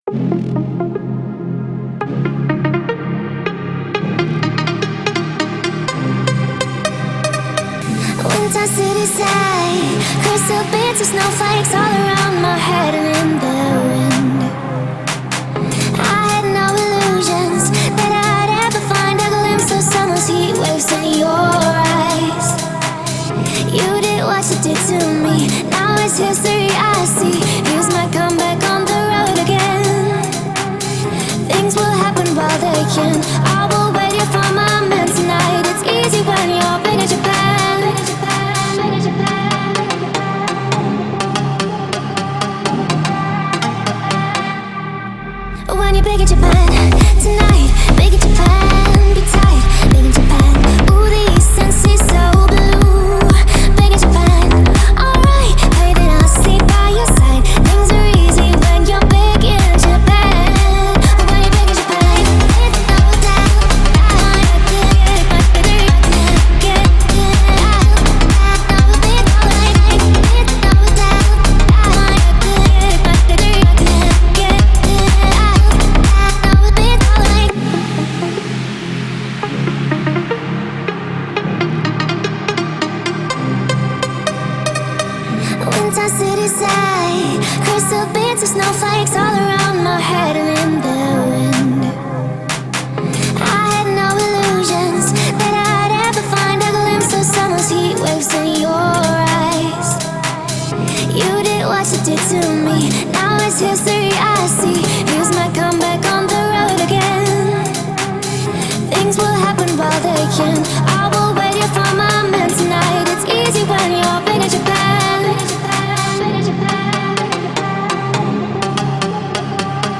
это динамичная и зажигательная трек в жанре EDM